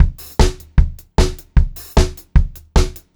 152SPBEAT3-R.wav